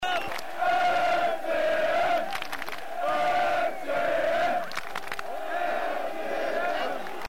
FCM-Fansongs und Blocklieder